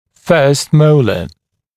[fɜːst ‘məulə][фё:ст ‘моулэ]первый моляр